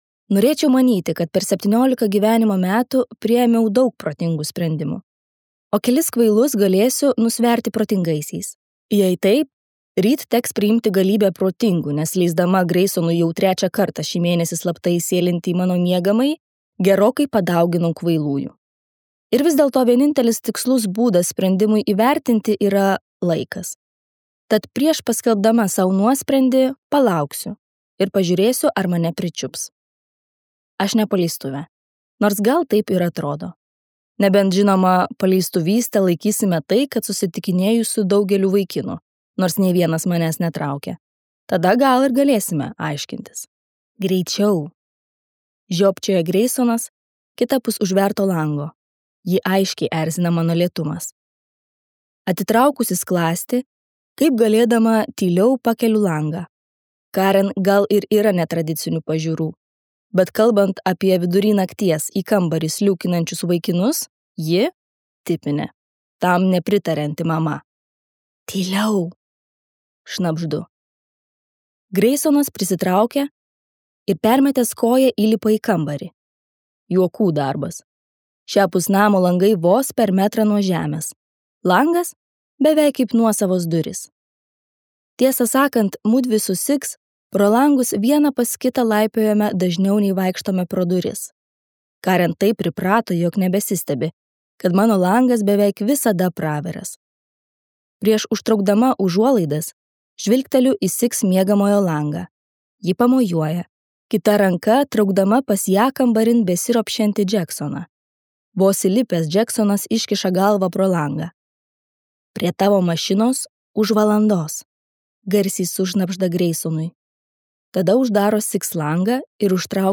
Colleen Hoover audioknyga „Netekę vilties“ – romanas, pasakojantis Skai ir Dino meilės istoriją. Tai gili ir jaudinanti kelionė į meilę, gijimą ir savęs atradimą.